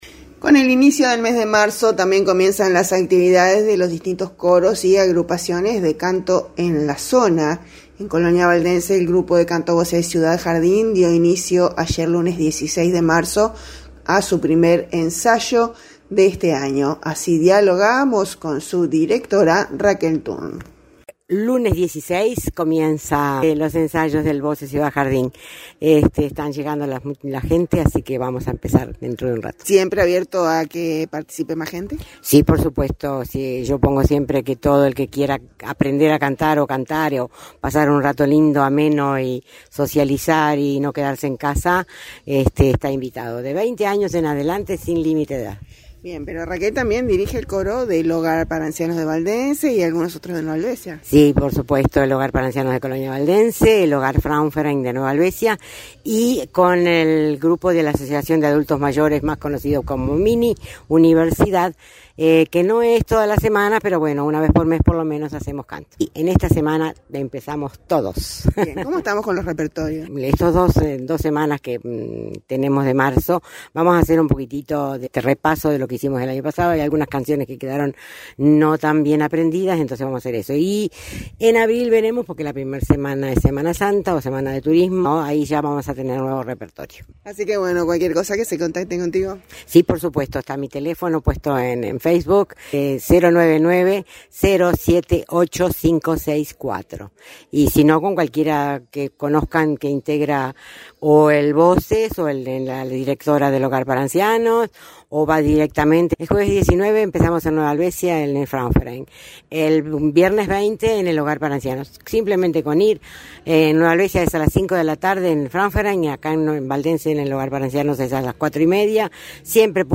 Así dialogábamos